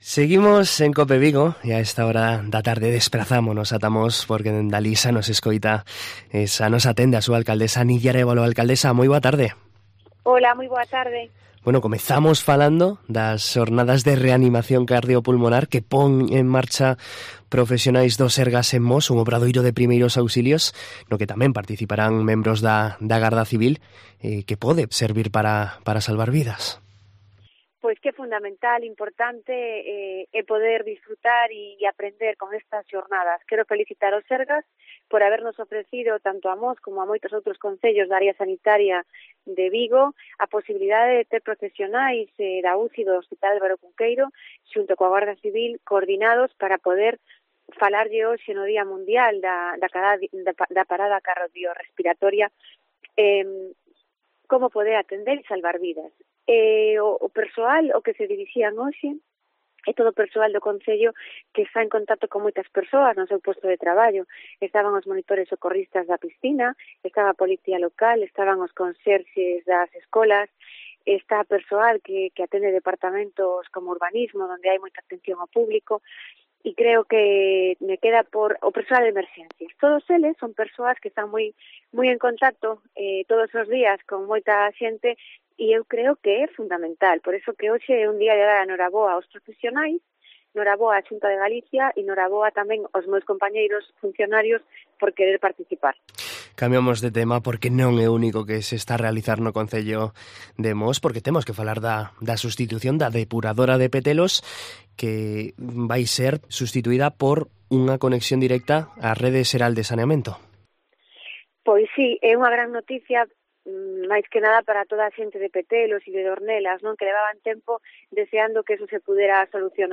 En COPE Vigo coñecemos a actualidade de Mos da man da súa alcaldesa